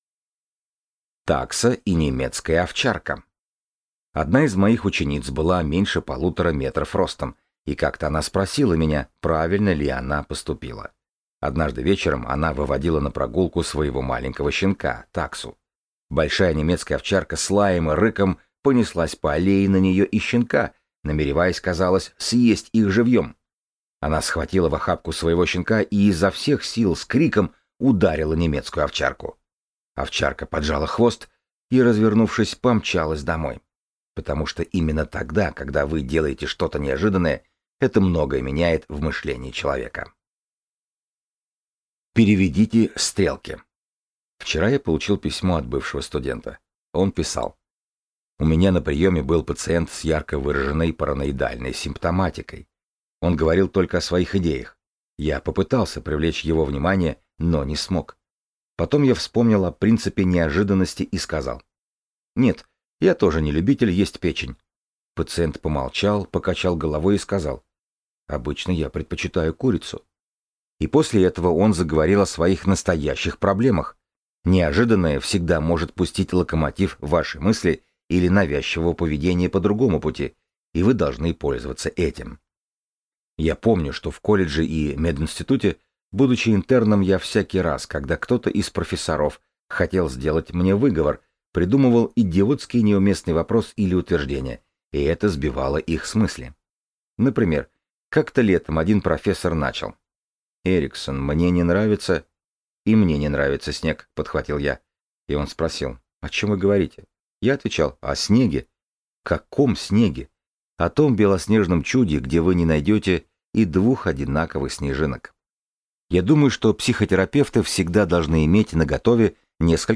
Аудиокнига Манипуляция и ориентация на будущее | Библиотека аудиокниг
Прослушать и бесплатно скачать фрагмент аудиокниги